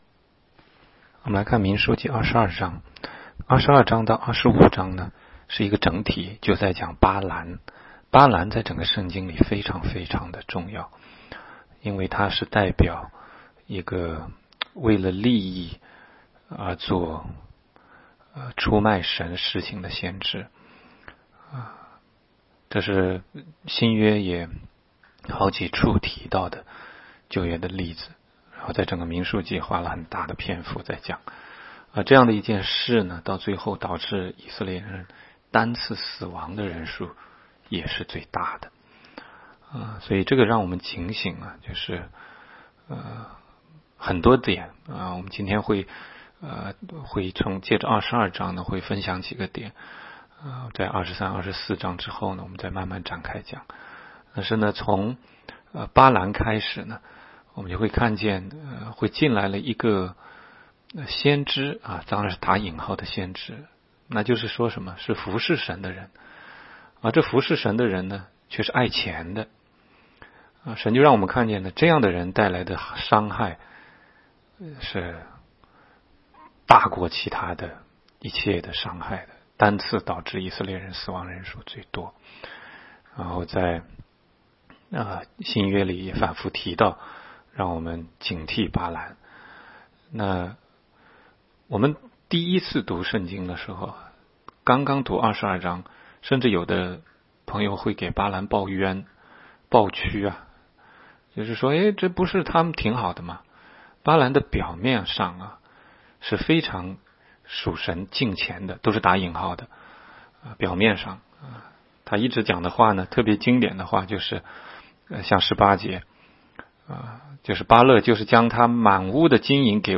每日读经